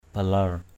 /ba-lar/ (cv.) bilar b{lR (đg.) buông lỏng, lơi lỏng. E. loosen up. juai balar gruk bac =j& blR g~K bC không nên lơi lỏng việc học.